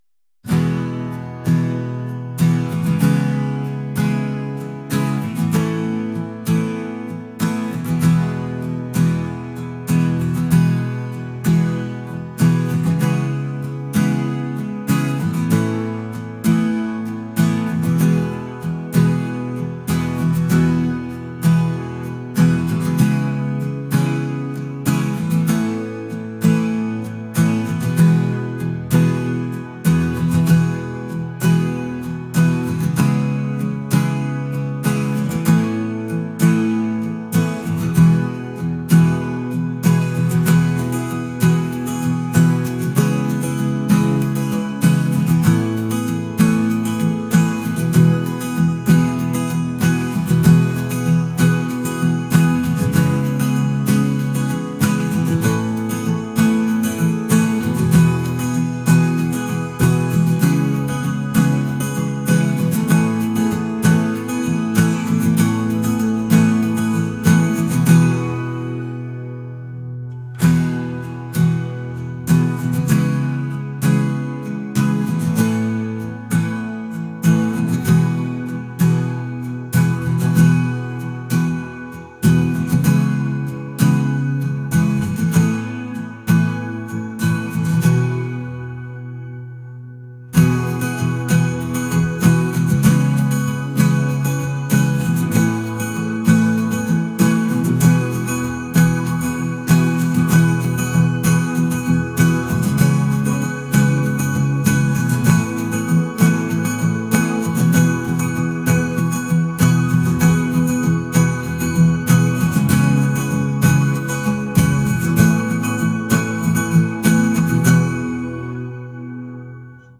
acoustic | pop | ambient